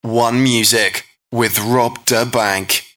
Below is the MP3 of the 'OneMusic with Rob da Bank' voice:
'OneMusic with Rob da Bank' voice (mp3)
voice.mp3